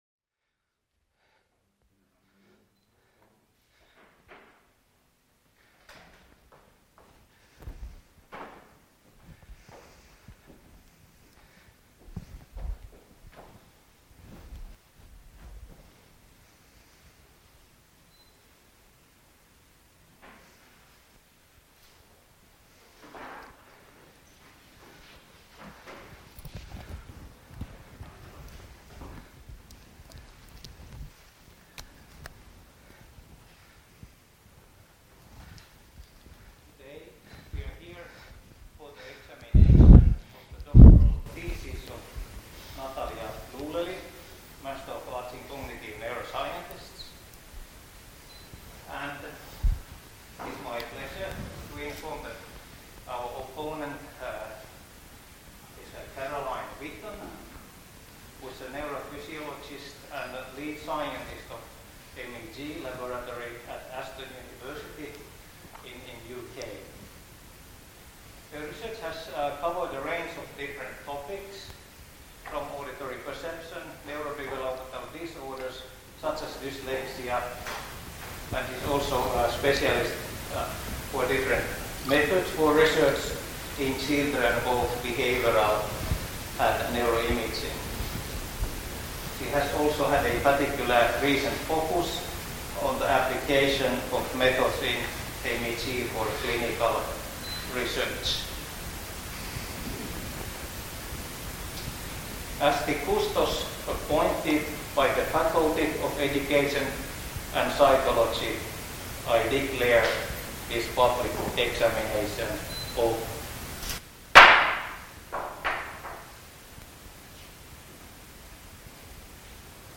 Public defense of PhD